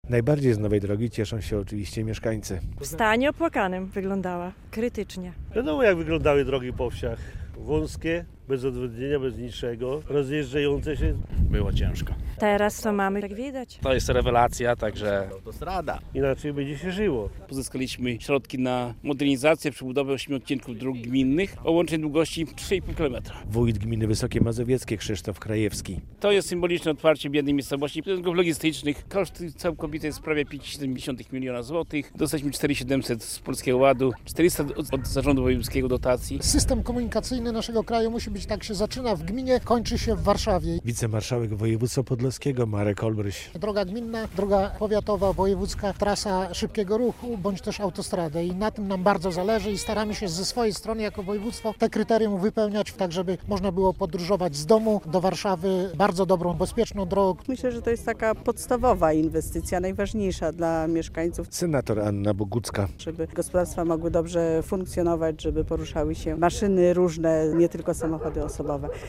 Nowe drogi w gminie Wysokie Mazowieckie - relacja